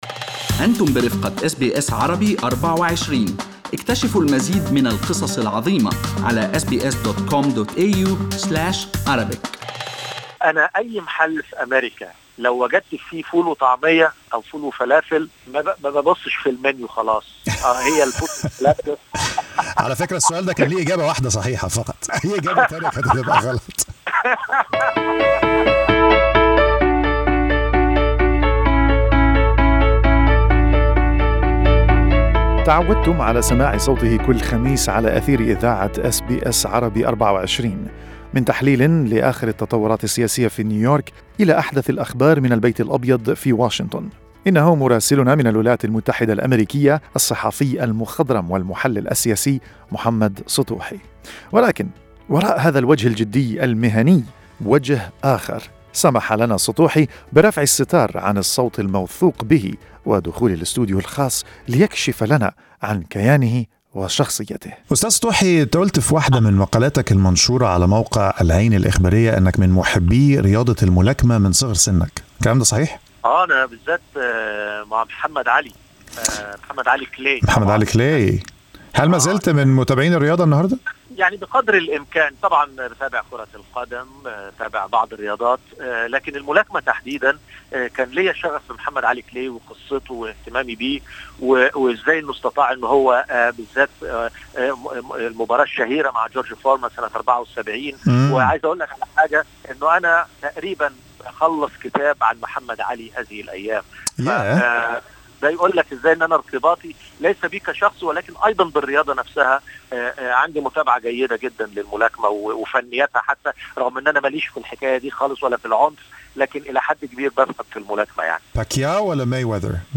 حوار خاص مع الأس بي أس عربي24